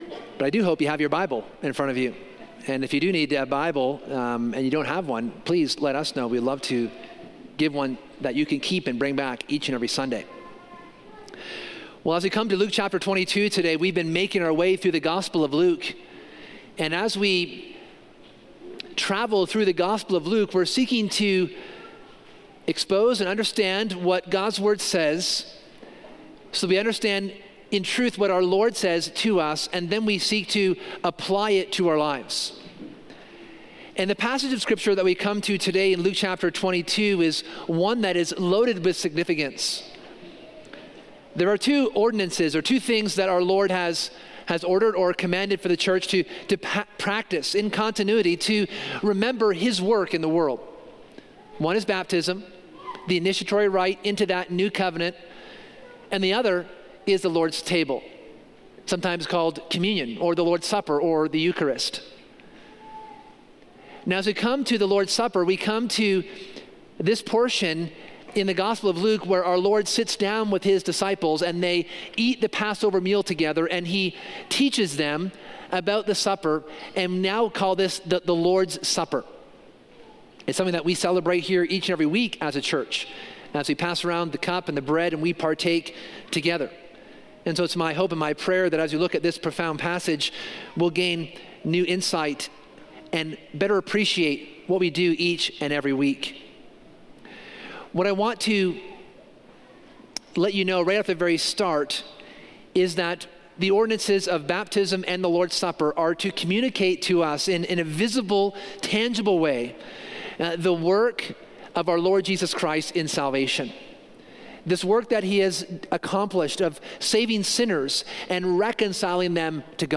In this sermon we consider in detail the institution of the Lord’s Supper.